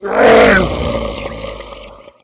Звуки орков
Звук умирающего орка